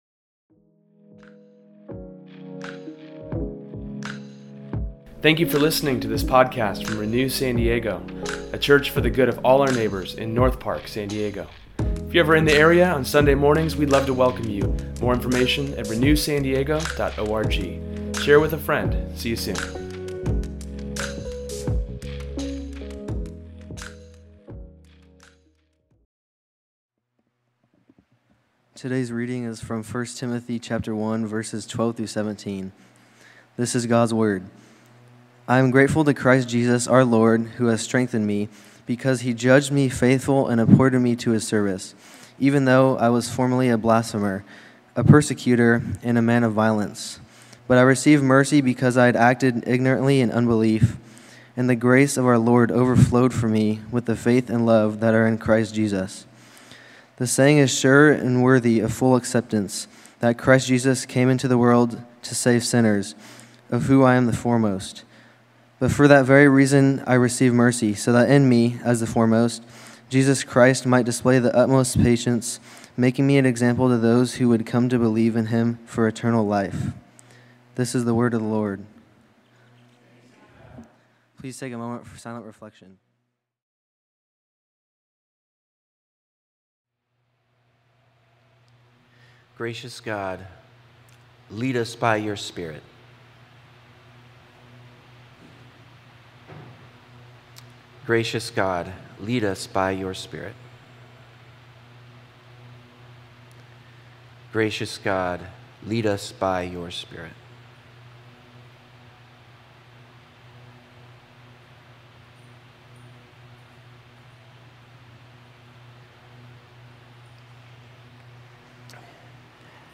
In today’s sermon, we breakdown the amazing grace God has for us, and how it sets us free.